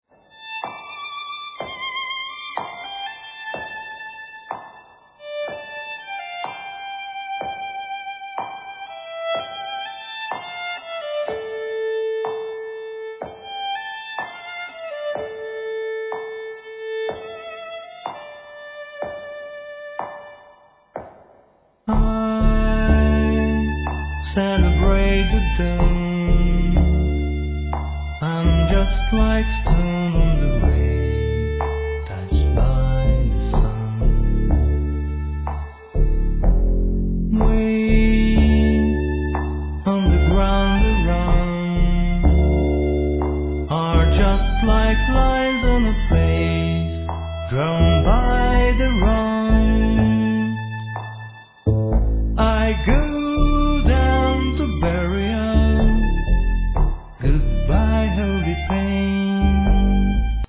Piano, Vocal